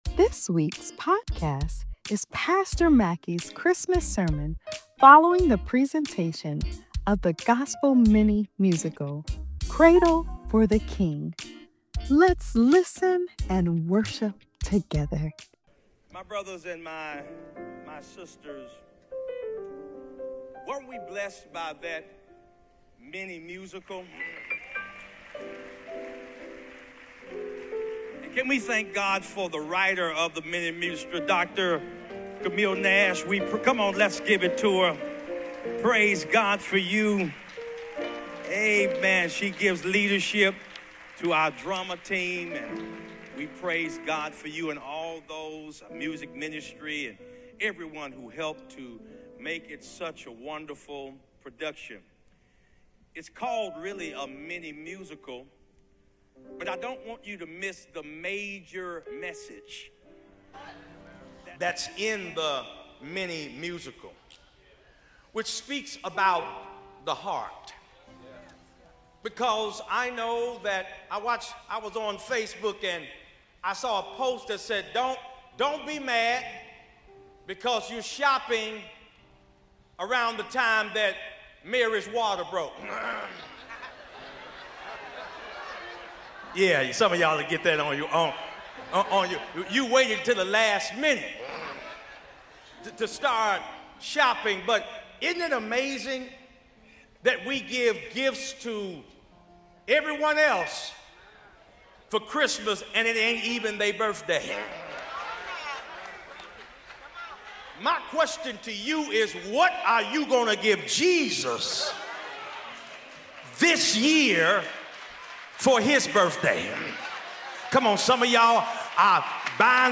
Christmas-Sermon.mp3